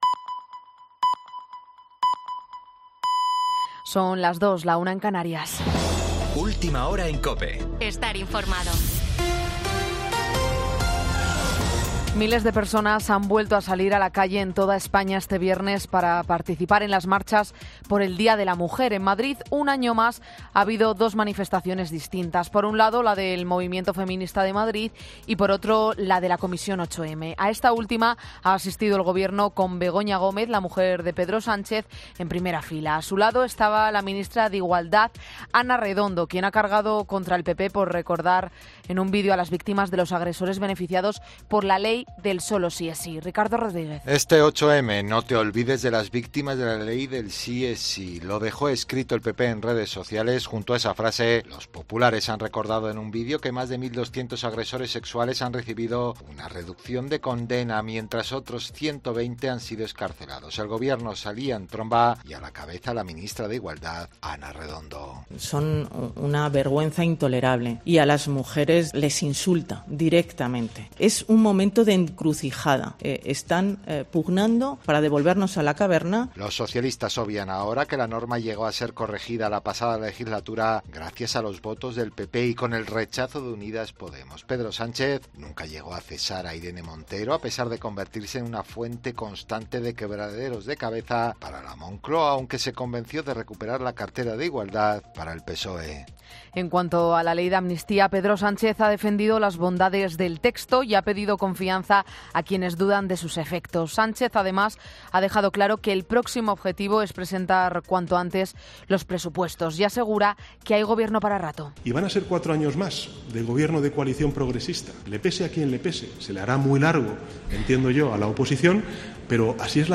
Boletín 02.00 horas del 9 de marzo de 2024